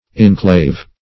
Search Result for " inclave" : The Collaborative International Dictionary of English v.0.48: Inclave \In*clave"\, a. [See Inclavated .]